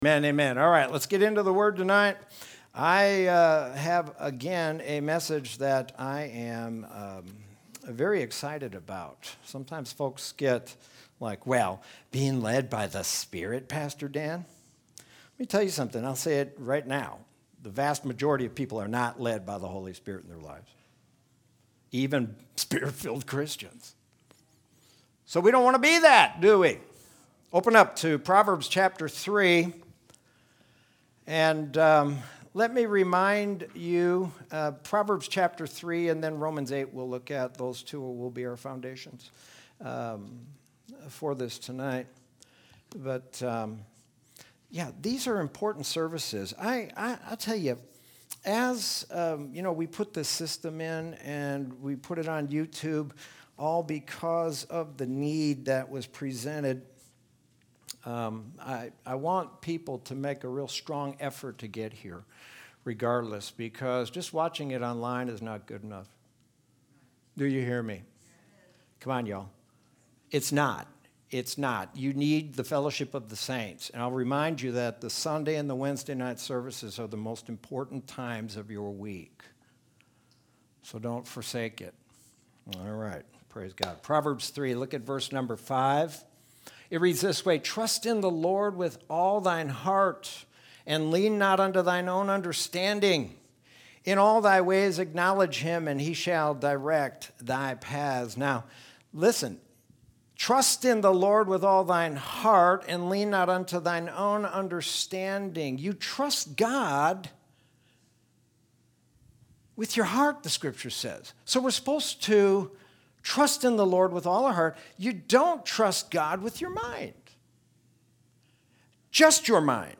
Sermon from Wednesday, September 16th, 2020.